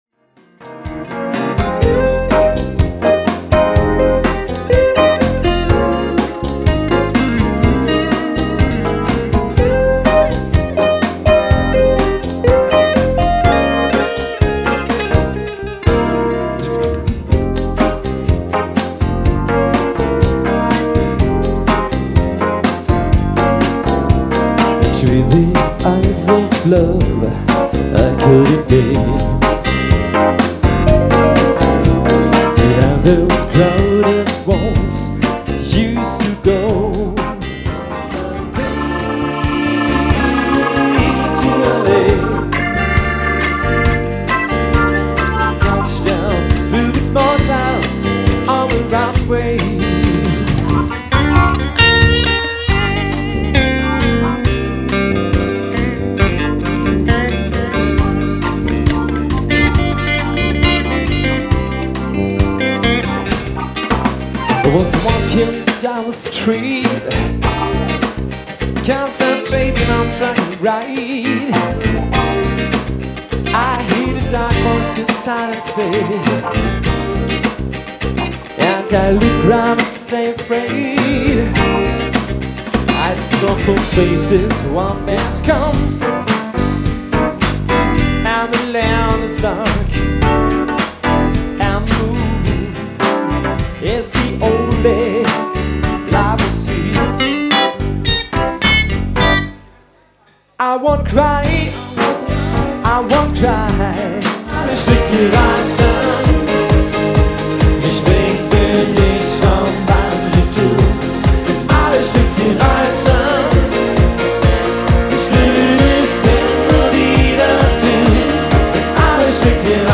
Trio aus NRW, Tanzmusik für jeden Anlass
• Demo-Medley (live!)